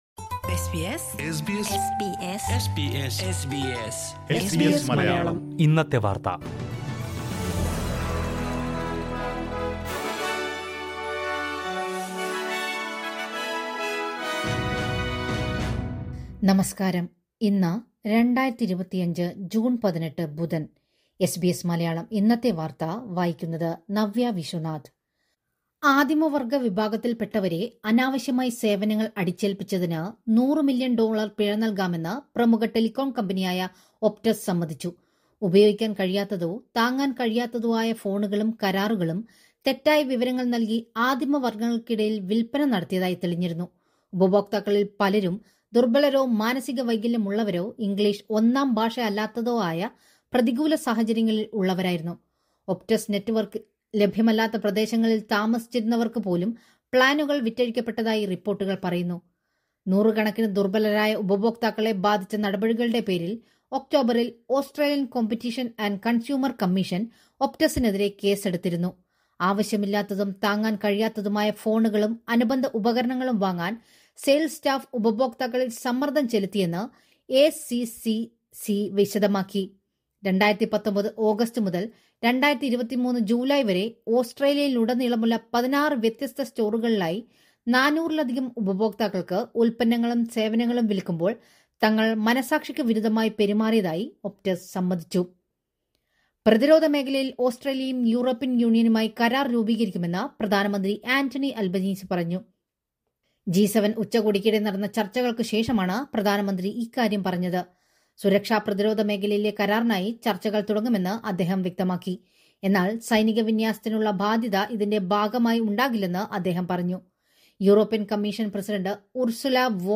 2025 ജൂണ്‍ 18ലെ ഓസ്‌ട്രേലിയയിലെ ഏറ്റവും പ്രധാന വാര്‍ത്തകള്‍ കേള്‍ക്കാം...